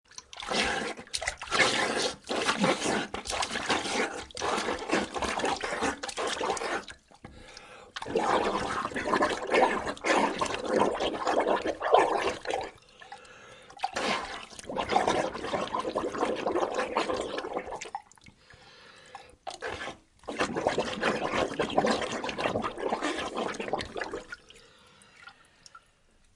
Âm thanh đuối nước, vùng vẫy trong nước
Thể loại: Hiệu ứng âm thanh
Description: Hiệu ứng âm thanh đuối nước, vùng vẫy trong nước ở dưới sông, hồ, biển sâu, nước bắn văng tung toé, bong bóng nước kêu, hơi thở của con người khi bơi trong nước, hì hục... Âm thanh thực, chân thực...
am-thanh-duoi-nuoc-vung-vay-trong-nuoc-www_tiengdong_com.mp3